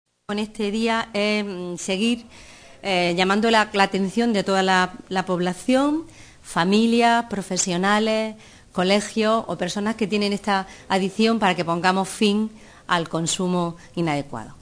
La Diputación Provincial de Almería ha presentado esta mañana en rueda de prensa las actividades que llevará a cabo con motivo del ‘Día Mundial Sin Alcohol’. Un conjunto de iniciativas organizadas desde el Área de Bienestar Social, Igualdad y Familia con las que se pretende concienciar a la sociedad de los perjuicios que causa el alcohol en la salud y en las relaciones sociales con los demás.